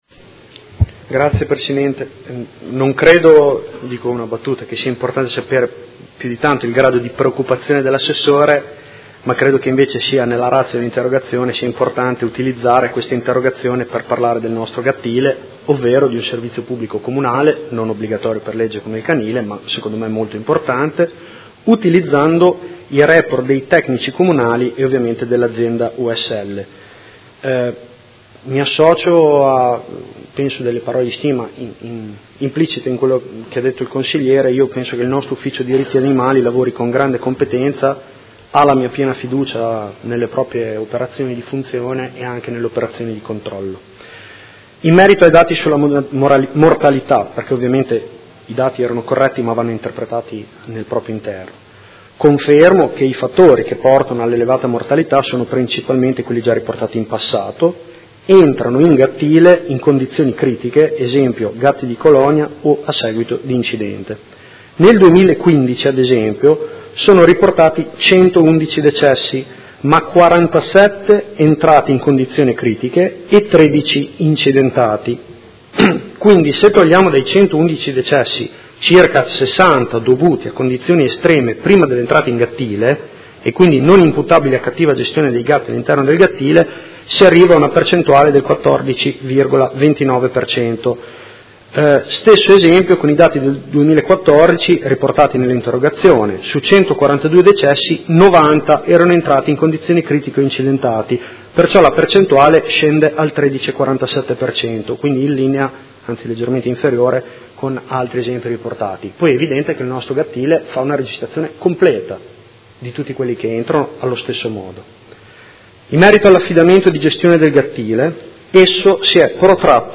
Seduta del 10/03/2016. Risponde a interrogazione del Gruppo Consiliare Movimento 5 Stelle avente per oggetto: Situazione del gattile intercomunale